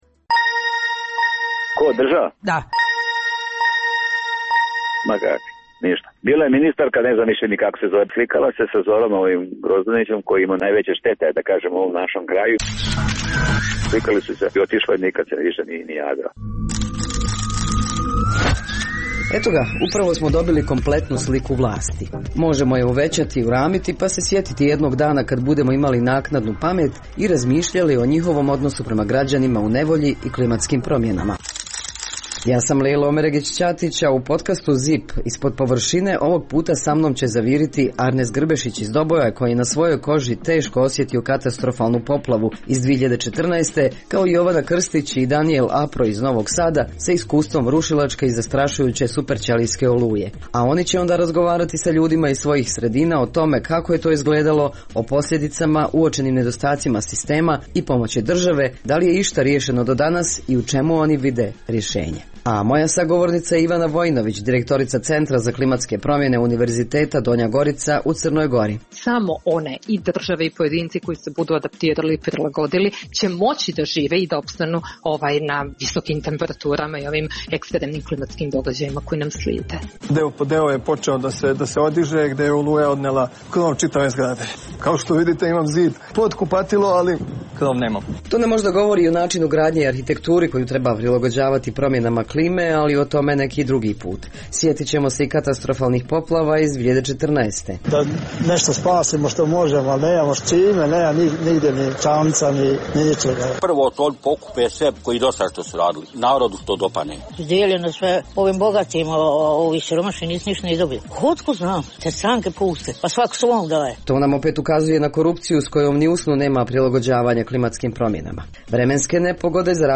Najpopularnije teme i reportaže iz programa Radija Slobodna Evropa.